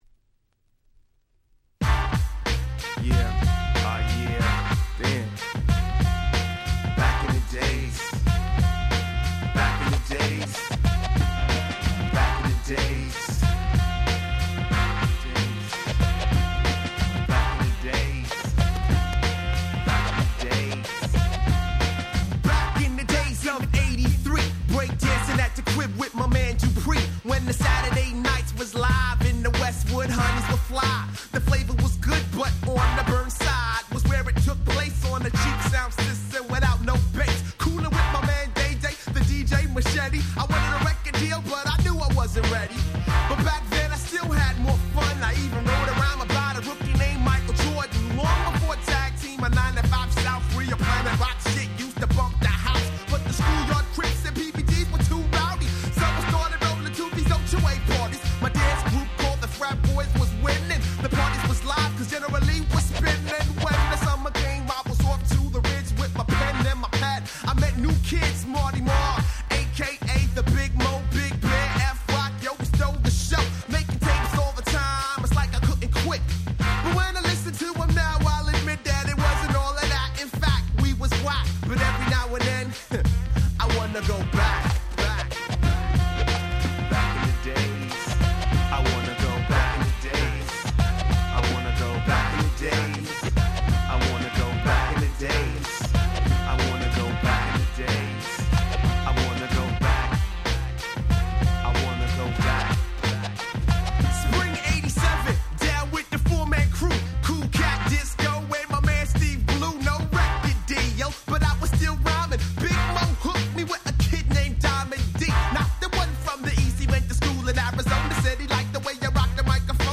94' Very Nice Hip Hop !!